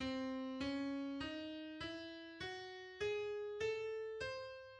Superlocrien bb7